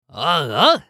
男性
熱血系ボイス～日常ボイス～
【楽しい1】